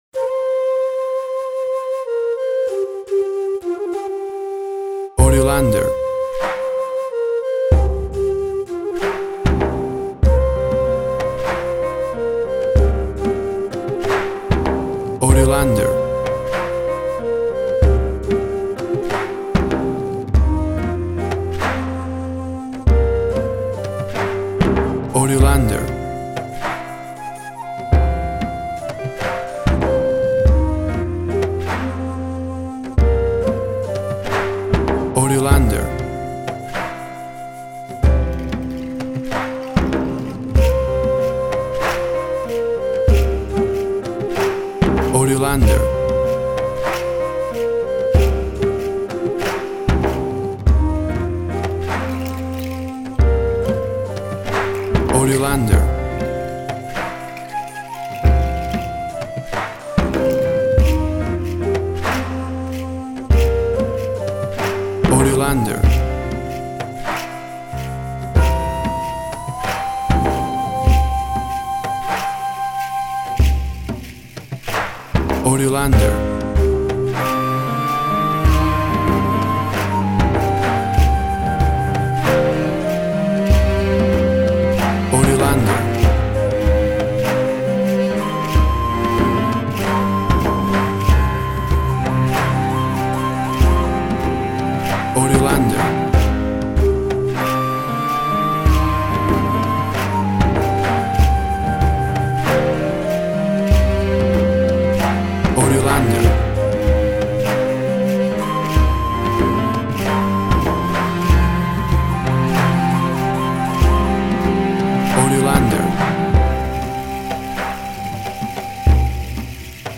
Forest environment. Shaman Flute.
WAV Sample Rate 16-Bit Stereo, 44.1 kHz
Tempo (BPM) 95